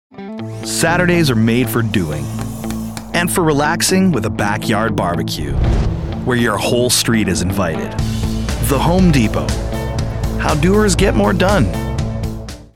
A Robust Baritone Full of Character
Home Improvement Demo - English